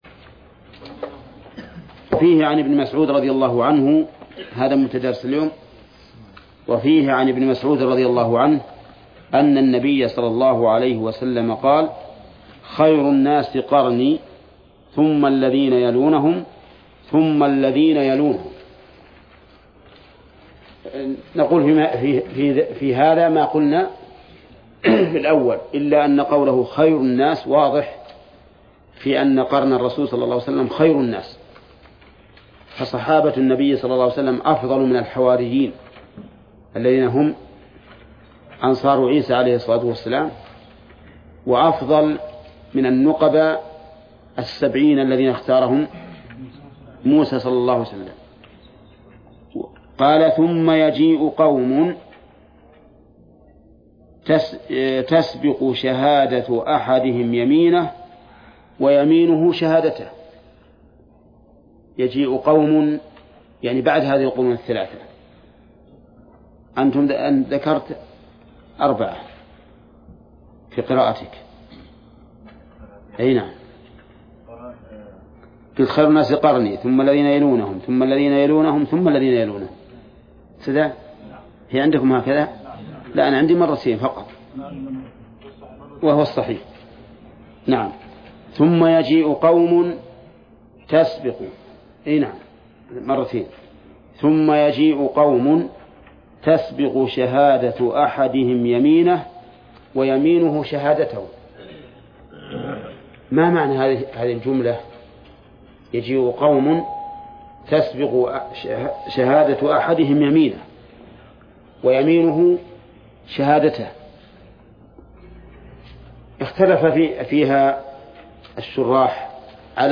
درس(52) / المجلد الثاني : من صفحة: (469)، قوله: (وفيه عن ابن مسعود: ..).، إلى صفحة: (490)، قوله: (ويستفاد من هذا الحديث:..).